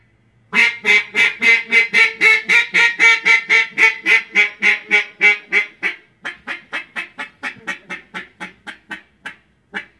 Звуки уток
Громкий звук утки